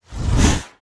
Index of /App/sound/monster/orc_magician
attack_2_eff.wav